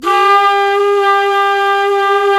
Index of /90_sSampleCDs/Roland LCDP04 Orchestral Winds/FLT_Jazz+Singin'/FLT_Singin'Flute